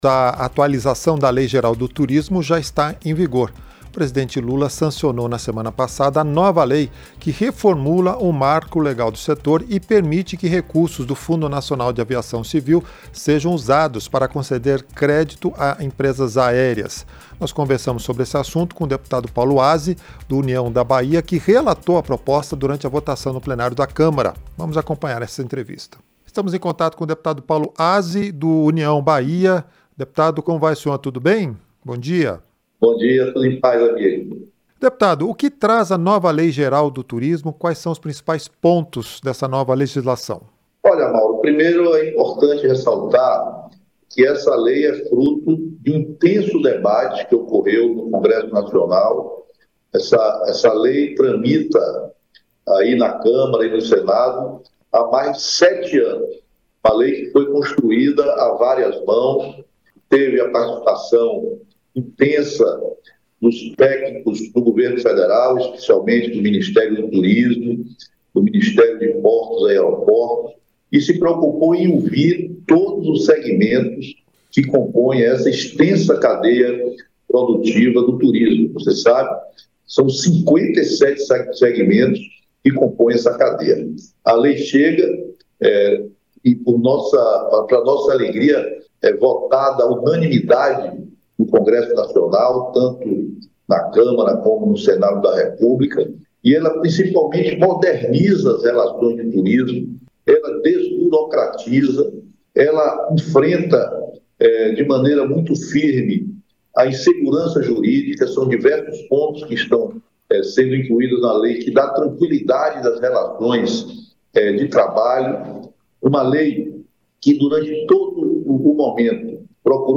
Entrevista - Dep. Paulo Azi (União-BA)